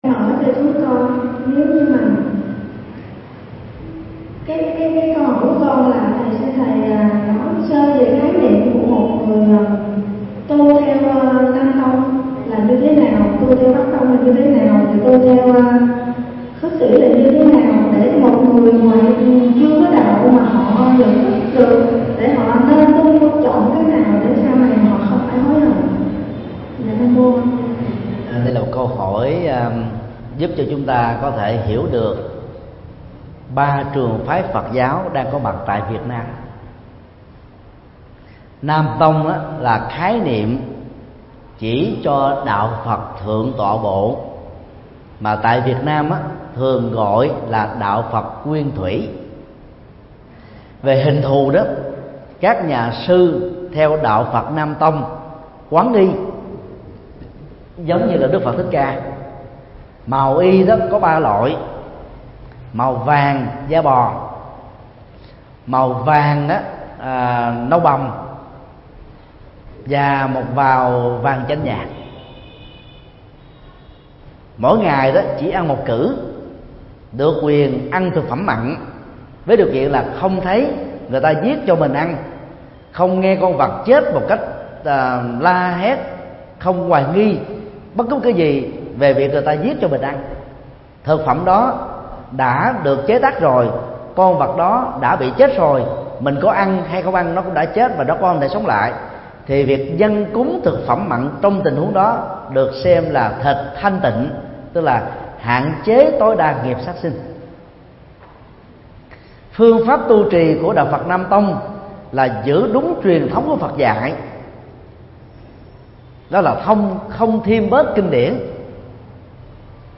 Vấn đáp: Nam tông, Bắc tông và Khất sĩ